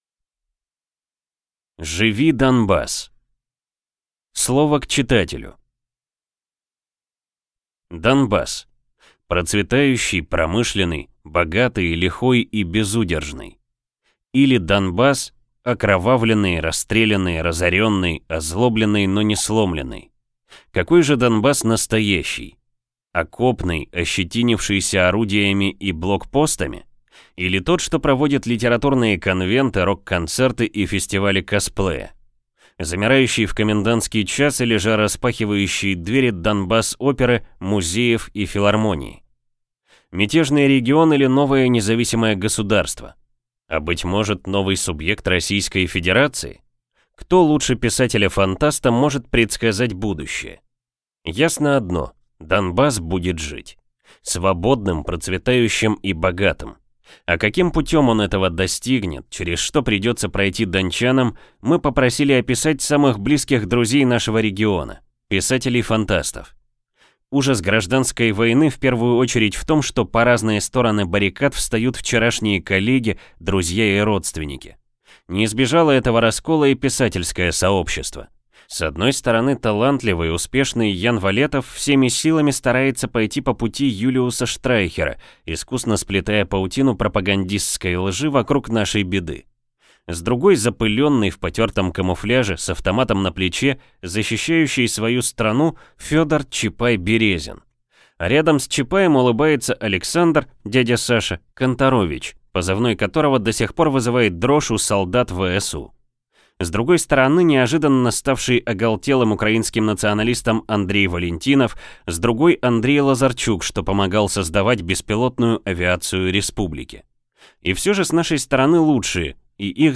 Аудиокнига Живи, Донбасс!